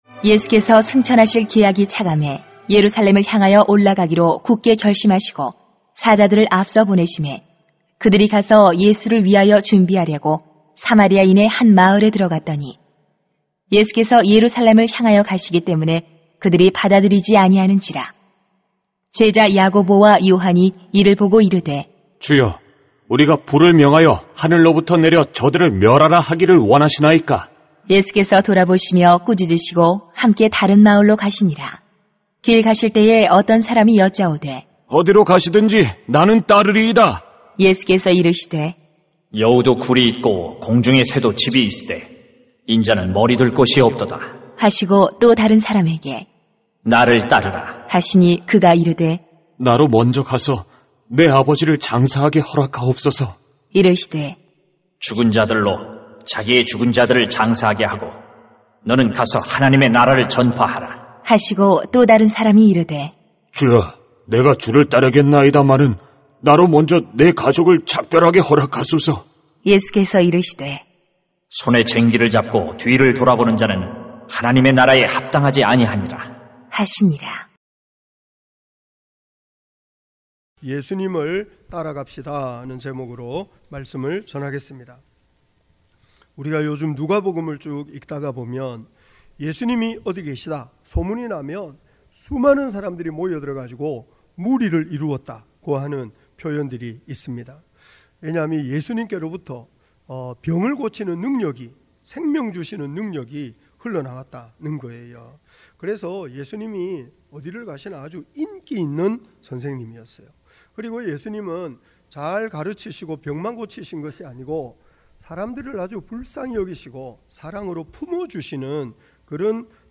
[눅 9:52-63] 예수님을 따라갑시다 > 주일 예배 | 전주제자교회